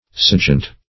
Search Result for " sejant" : The Collaborative International Dictionary of English v.0.48: Sejant \Se"jant\, Sejeant \Se"jeant\, a. [F. s['e]ant, p. pr. of seoir to sit, L. sedere.]
sejant.mp3